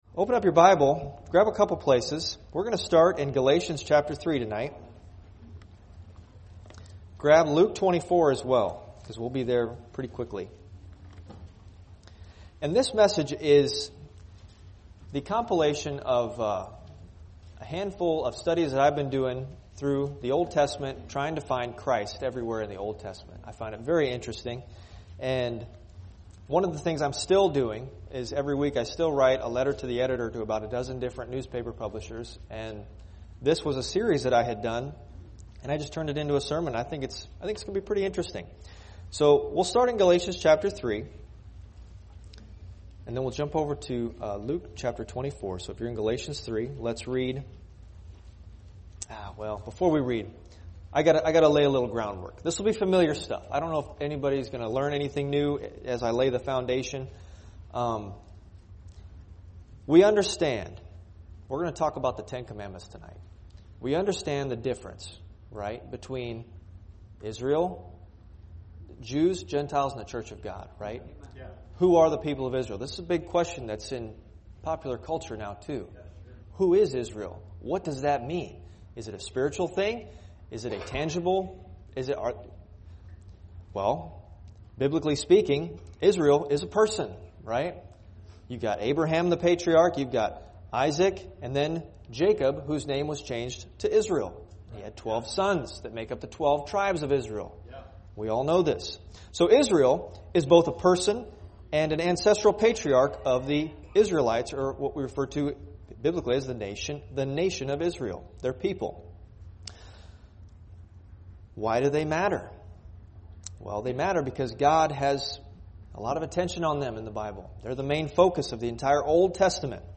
Jesus Meets a Monster – Luke 8 | Sunday School